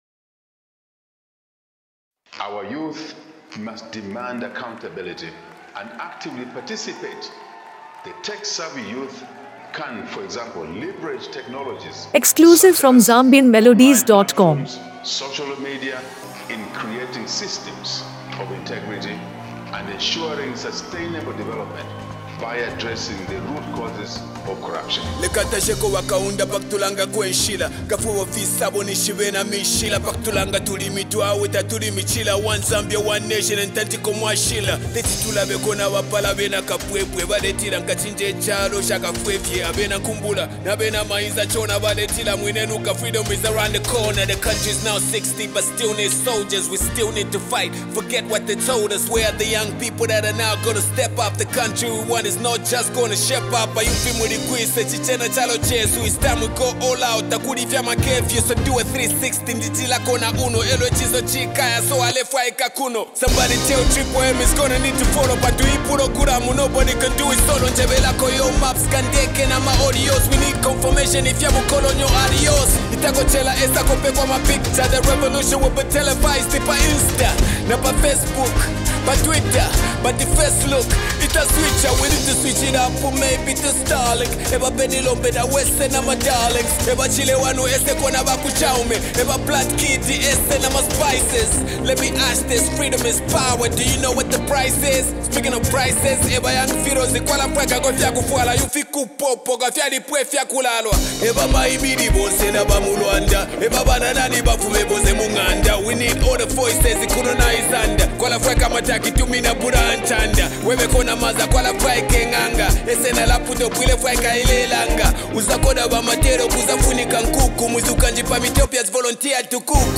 With a blend of hip-hop and Afro-fusion sounds